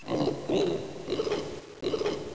zombi_bomb_idle_9.wav